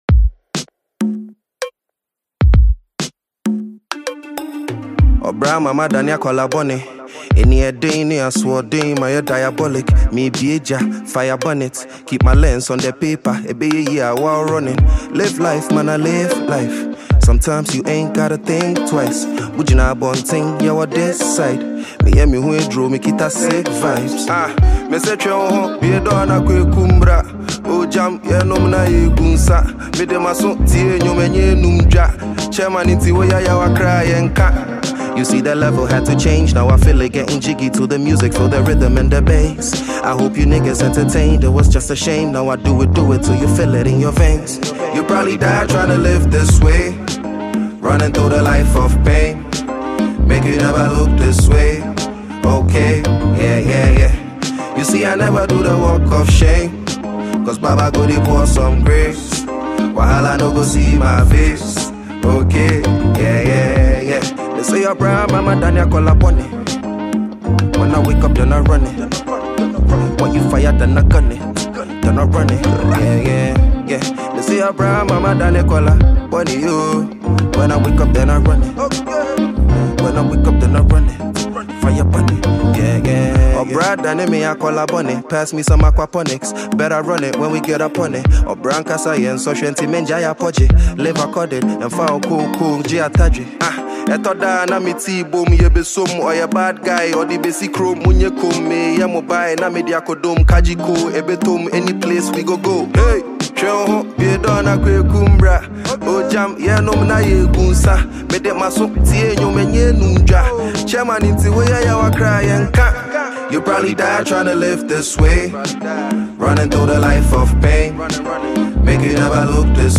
Enjoy this amazing Afrobeat production.